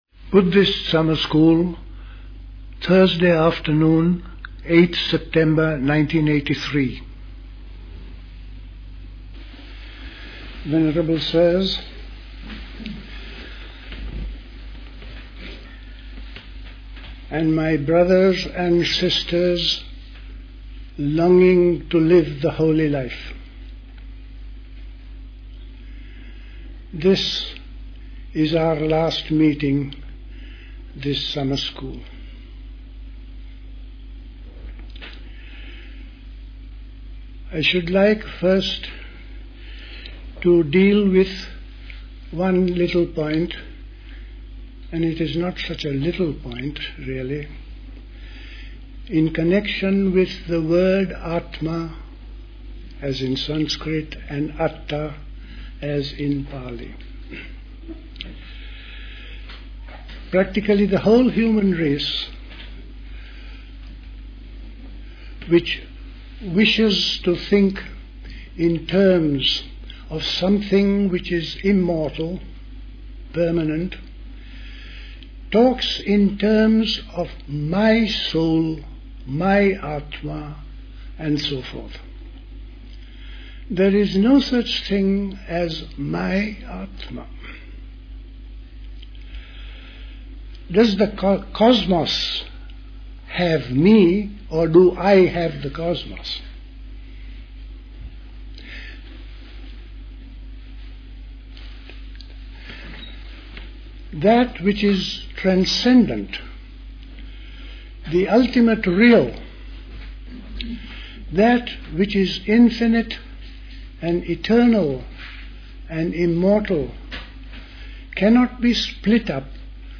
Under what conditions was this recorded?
The Buddhist Society Summer School